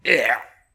wormball_yuck.ogg